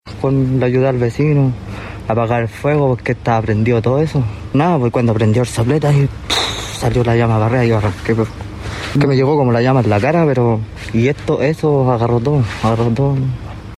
En tanto, un vecino resultó con lesiones menores a raíz de lo ocurrido.